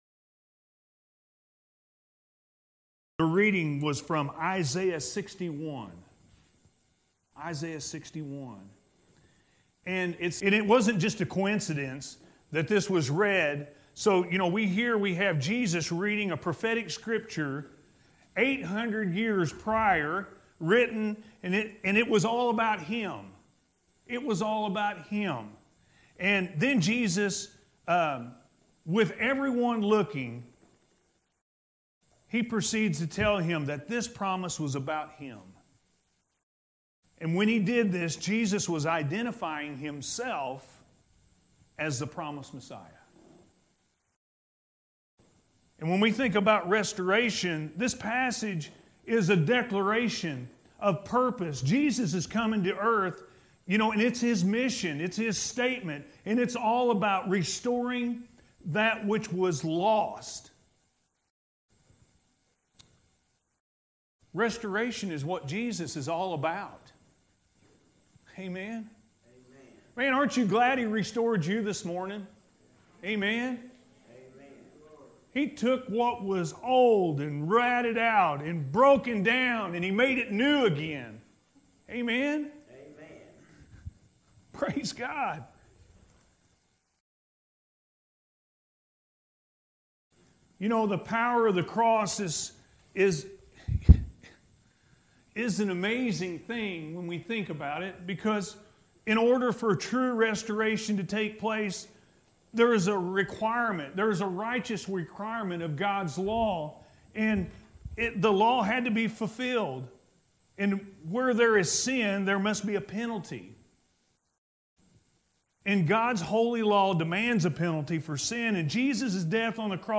Restoration Comes From God-A.M. Service – Anna First Church of the Nazarene